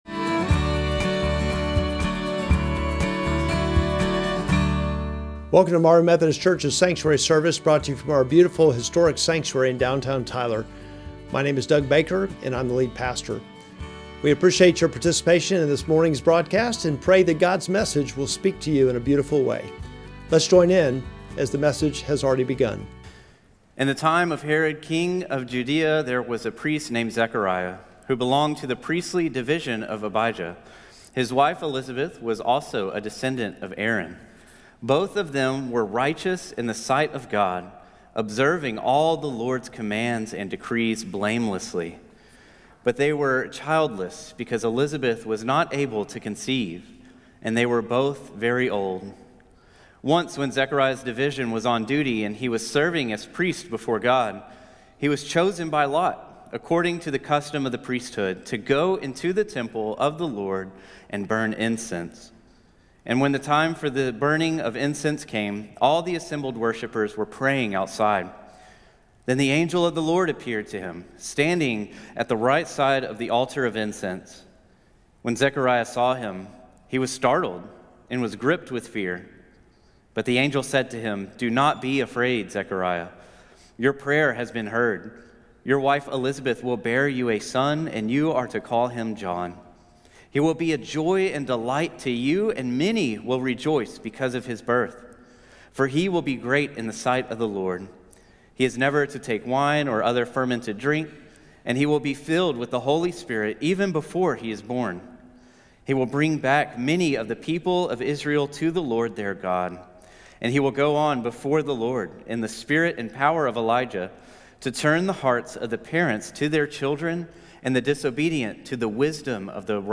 Sermon text: Luke 1:5-25